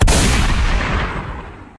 gun4.ogg